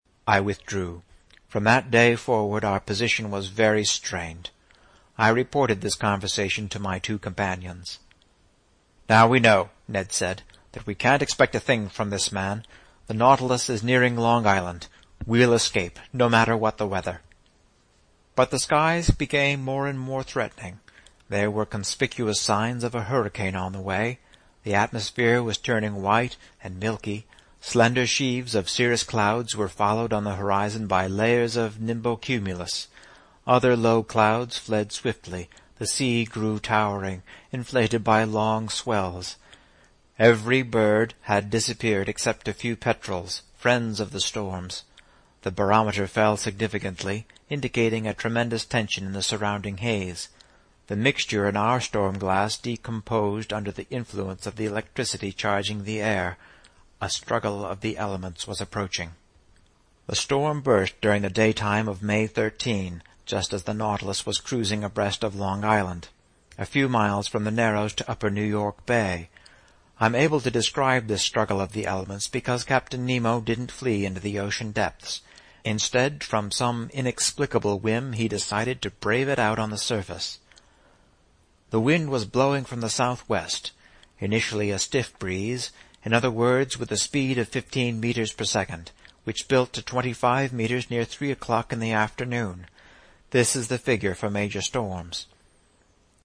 在线英语听力室英语听书《海底两万里》第522期 第32章 海湾暖流(13)的听力文件下载,《海底两万里》中英双语有声读物附MP3下载